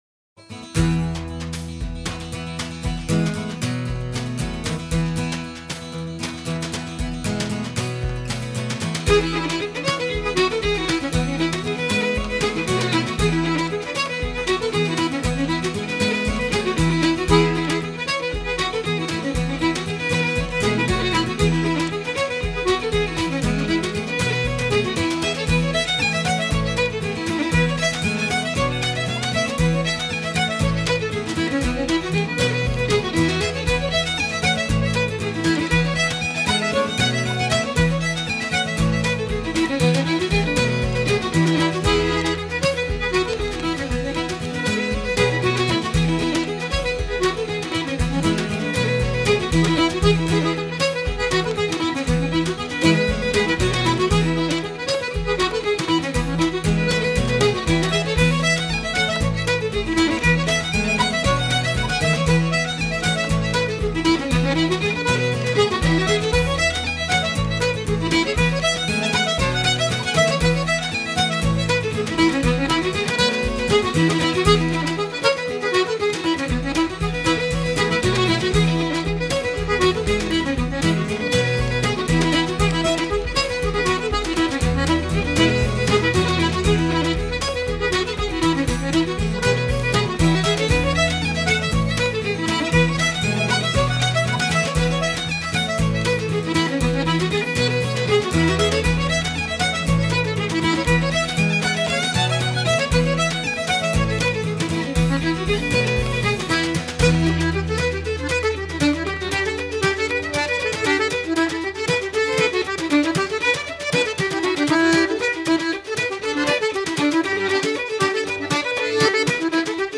fiddle, concertina, harmony vocals
button accordion
guitar, vocals
percission, mandolin, harmony vocals